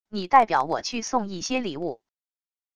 你代表我去送一些礼物wav音频生成系统WAV Audio Player